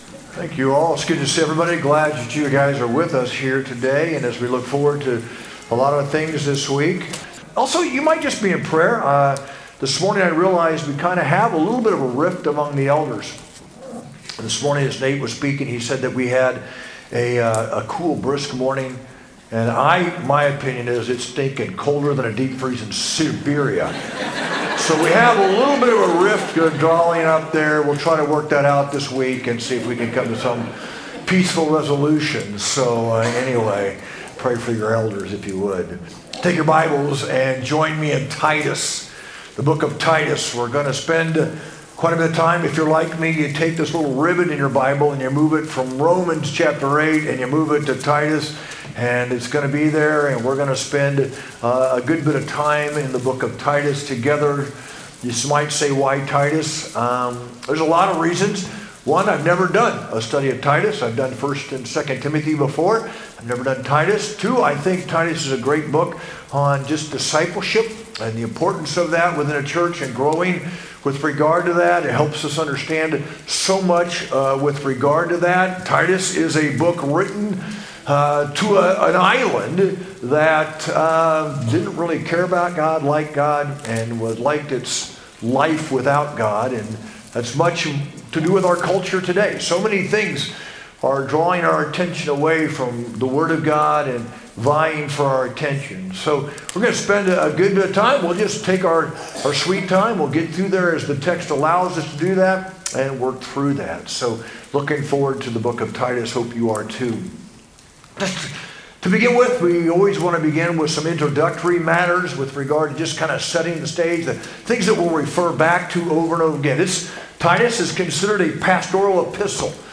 sermon-2-16-25.mp3